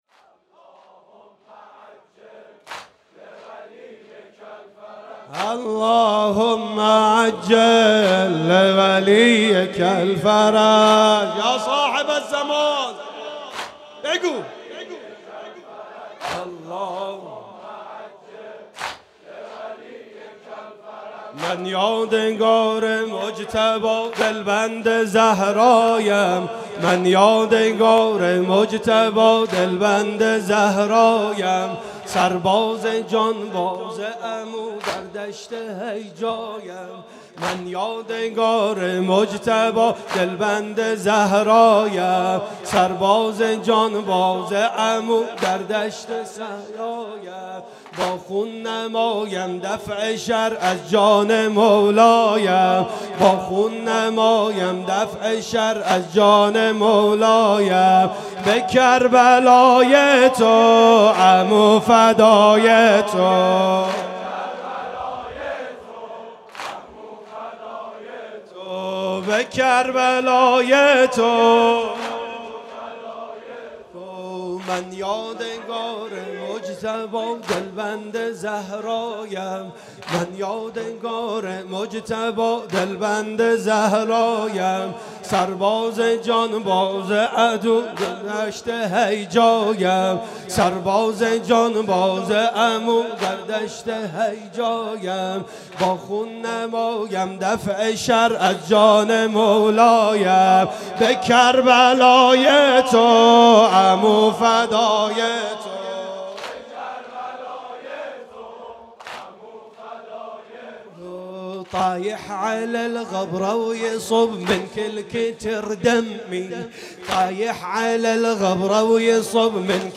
گلچين محرم 95 - واحد - عمو فدای تو